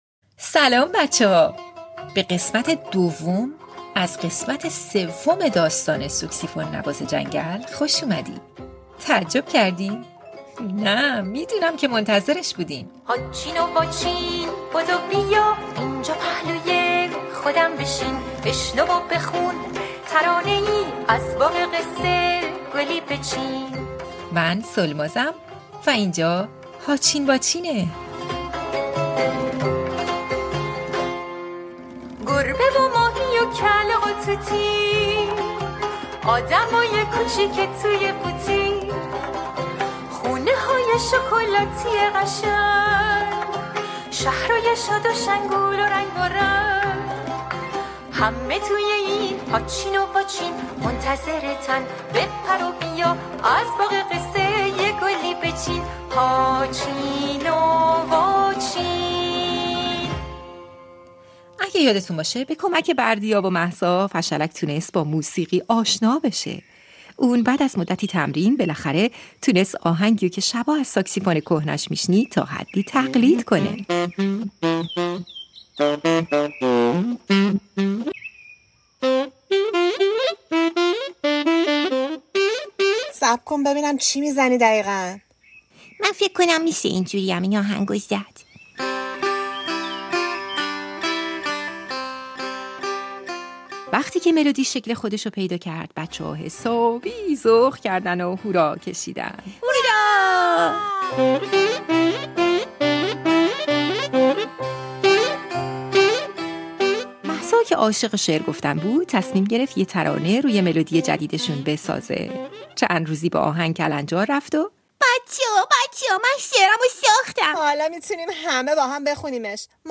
پادکست «هاچین واچین» اولین کتاب صوتی رادیو فردا، مجموعه قصه‌هایی برای کودکان است.